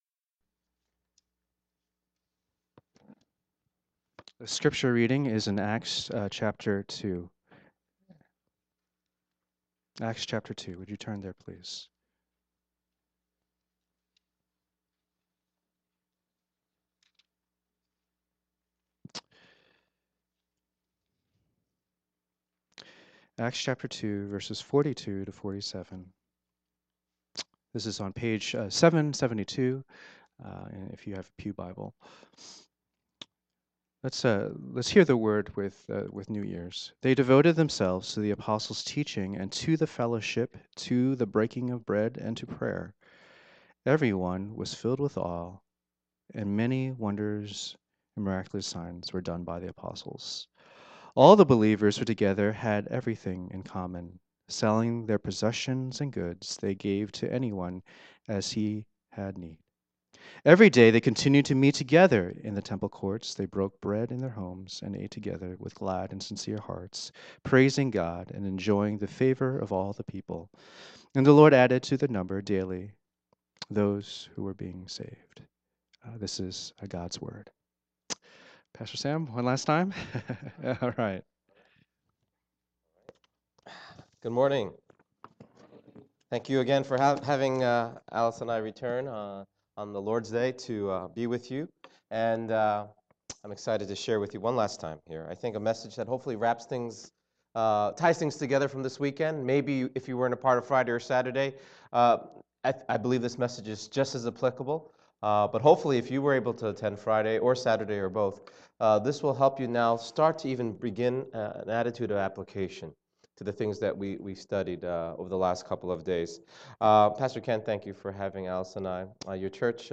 Relationships Seminar Passage: Acts 2:42-47 Service Type: Lord's Day %todo_render% « Courageous Parenting